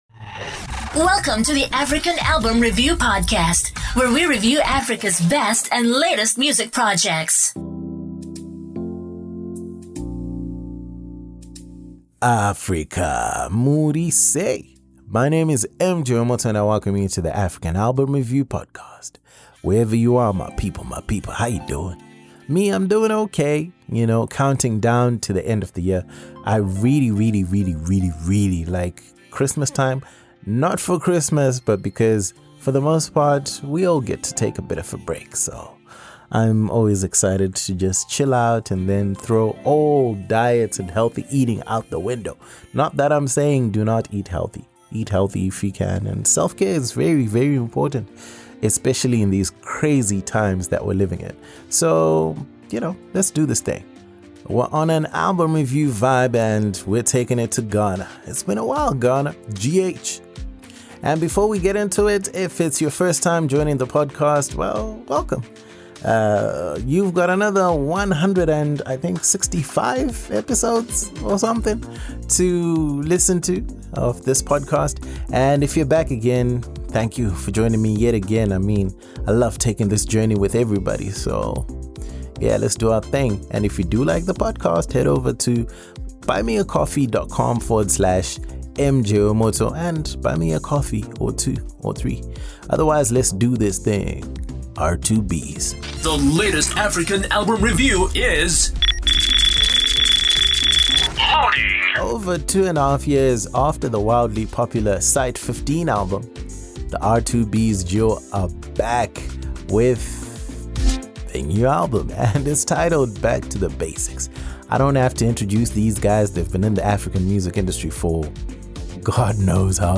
R2Bees – Back 2 Basics ALBUM REVIEW Ghana ~ African Album Review Podcast